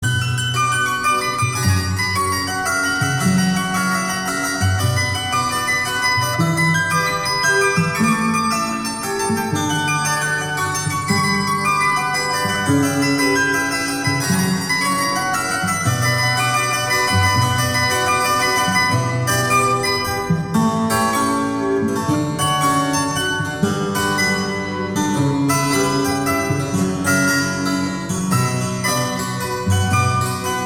• Качество: 320, Stereo
инструментальные
романтика